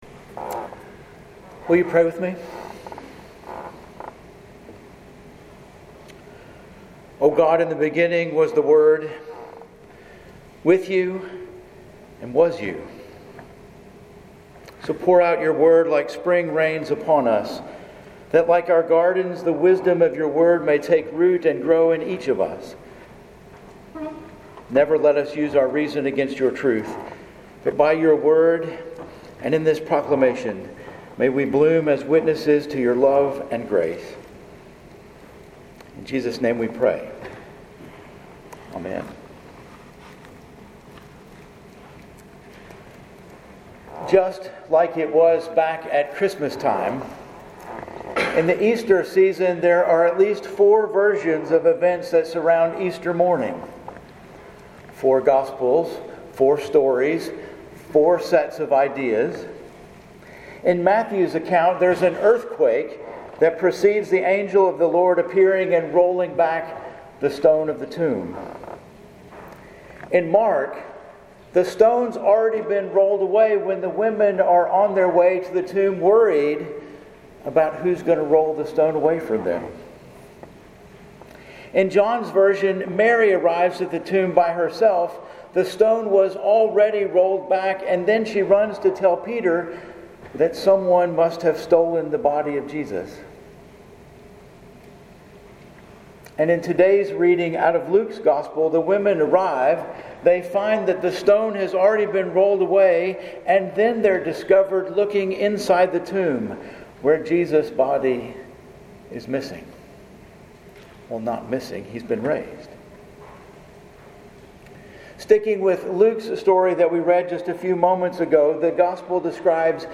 Sermons at First Presbyterian Church El Dorado, Arkansas